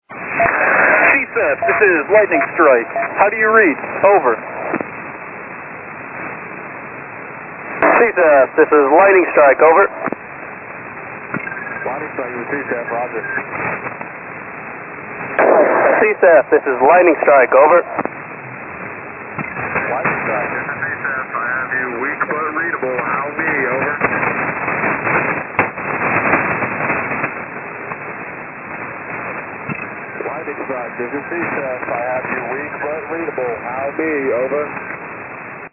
Lightning strike